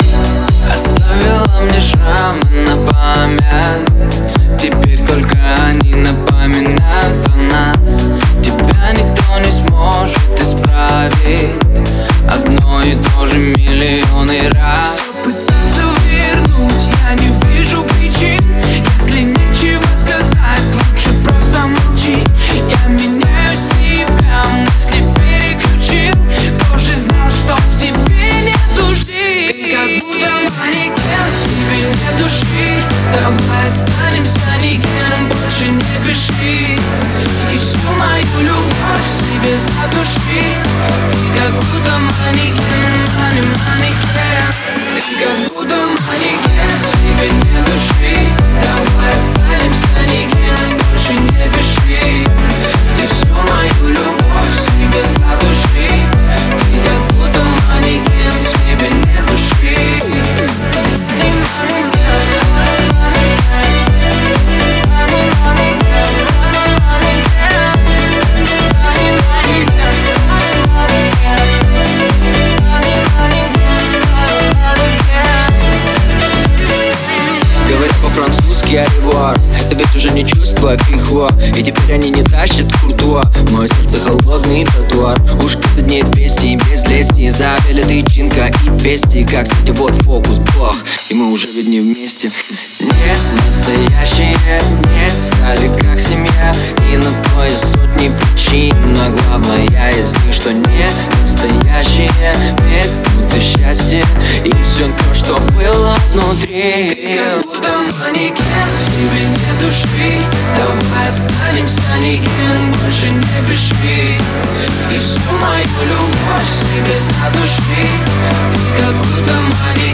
это энергичная электронная композиция
которая сочетает в себе элементы поп и EDM.
а мощные биты заставляют двигаться в ритме музыки.